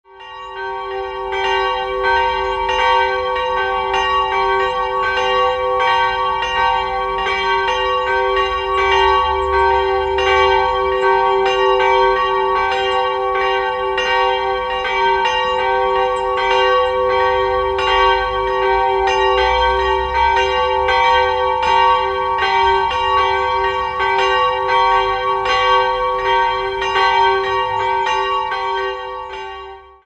Die Ursprünge reichen bis in gotische Zeit zurück, wobei die Kirche 1702 bis 1708 erweitert wurde. 2-stimmiges Kleine-Terz-Geläute: g''-b'' Die Glocke g'' stammt von Friedrich Wilhelm Schilling (Heidelberg) aus dem Jahr 1970 und wiegt 106 kg. Die kleine b''-Glocke ist vermutlich historisch.